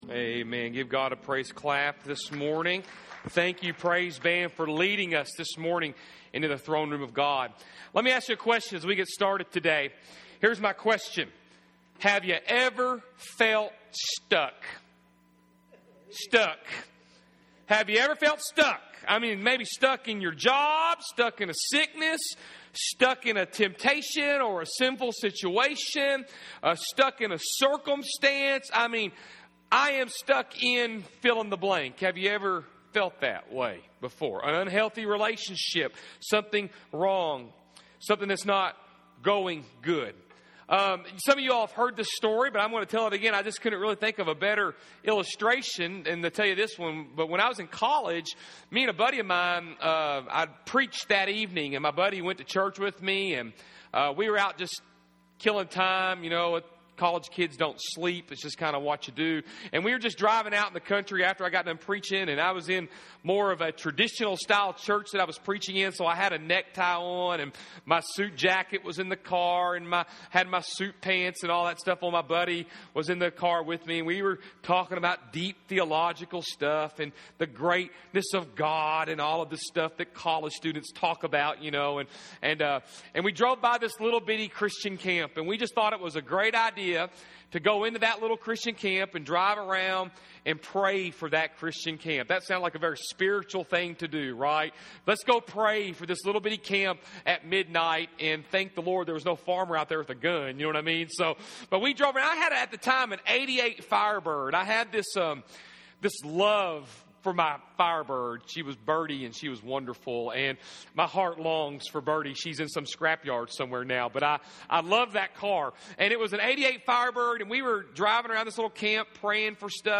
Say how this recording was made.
May 17, 2015 Breakthrough Prayer- The Proper Approach Service Type: Sunday AM First message in the series "BREAK THROUGH PRAYER".